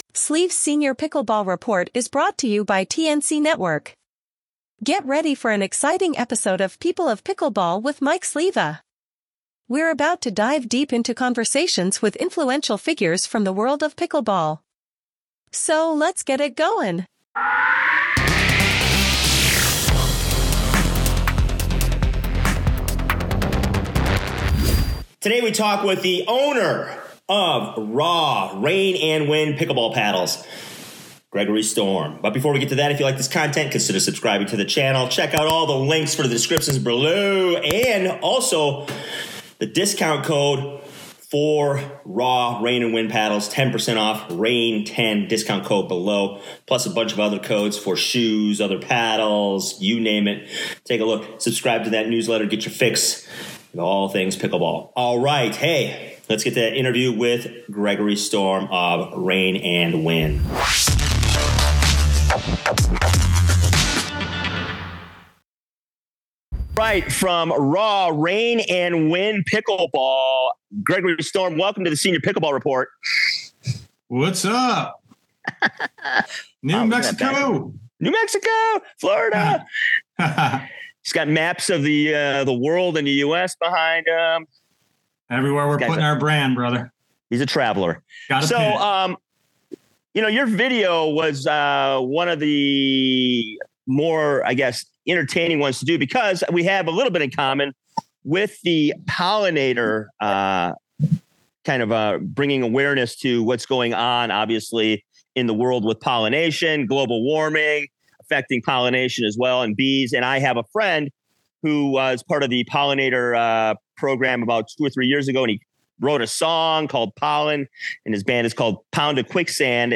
RAW-Interview-mixdown.mp3